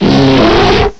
cry_not_pangoro.aif